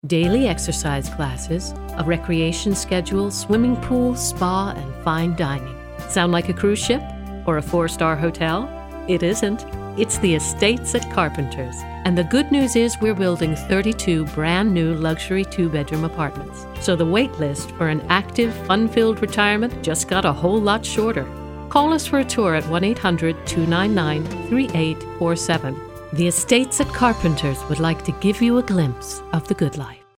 Commercials
Voiceover